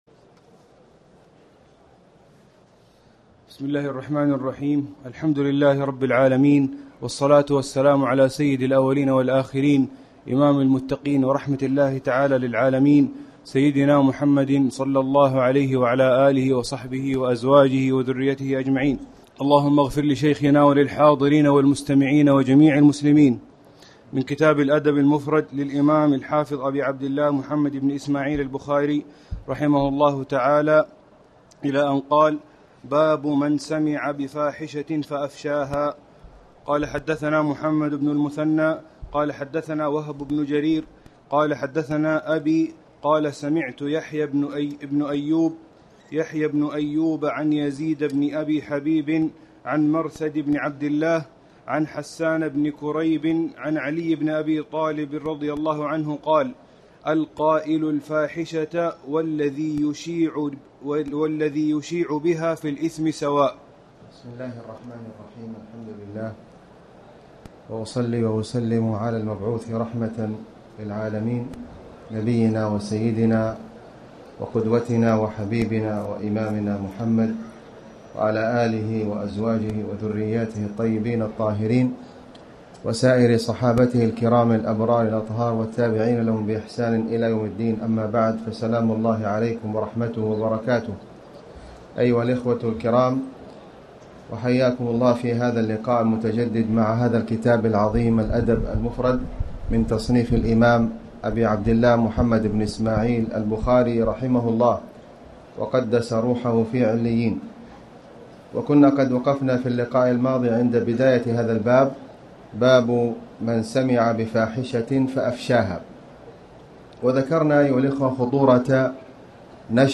تاريخ النشر ٧ ربيع الثاني ١٤٣٩ هـ المكان: المسجد الحرام الشيخ: خالد بن علي الغامدي خالد بن علي الغامدي باب من سمع بفاحشه فأفشاها